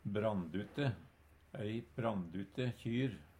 brandute - Numedalsmål (en-US)
Høyr på uttala Ordklasse: Adjektiv Kategori: Uttrykk Attende til søk